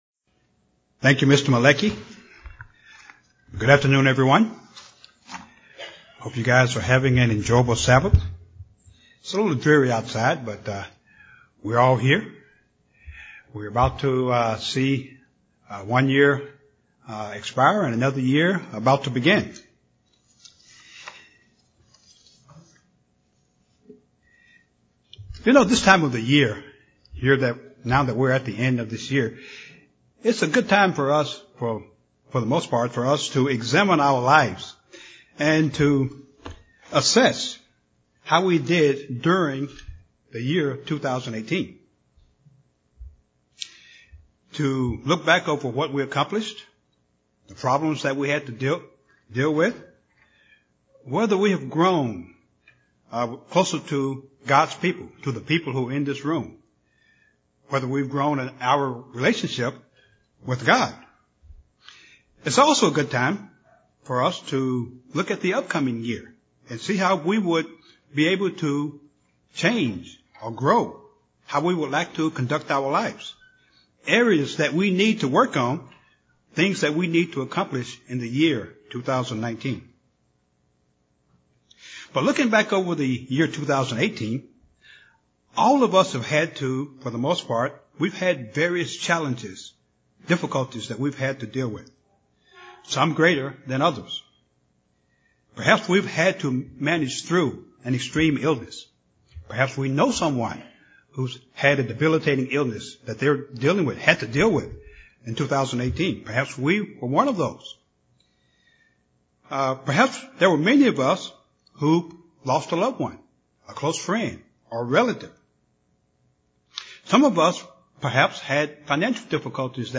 Given in Little Rock, AR